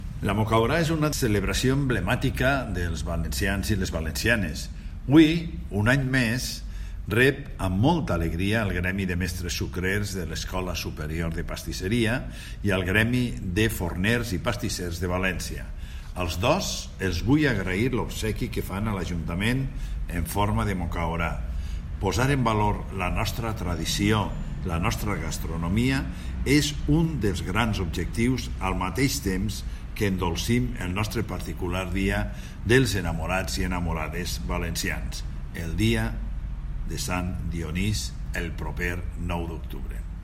La Trobada del Govern de la Nau tendrá lugar este sábado, 6 de octubre, tal como ha anunciado hoy en alcalde de València, Joan Ribó, a preguntas de los medios de comunicación.